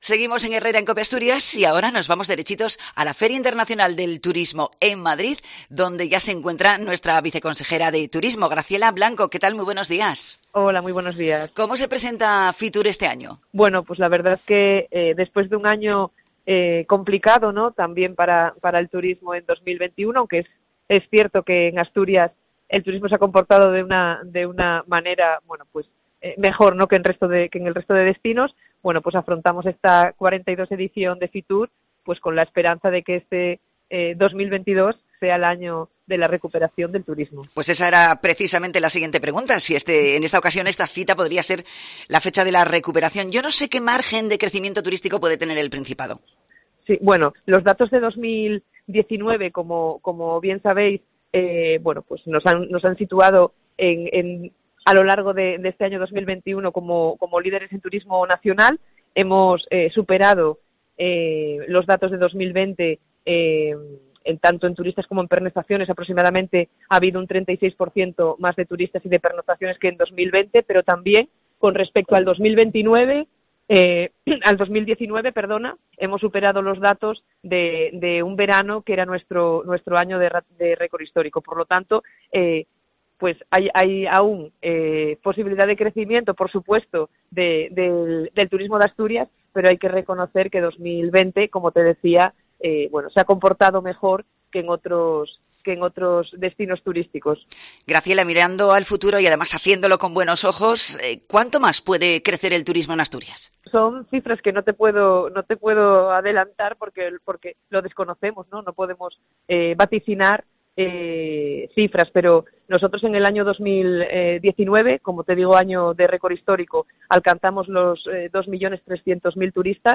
La viceconsejera de Turismo del Principado ha participado en el programa especial de Fitur de COPE Asturias: "Esperamos que 2022 sea el año de la recuperación del sector"
Fitur 2022: Entrevista a Graciela Blanco, viceconsejera de Turismo del Principado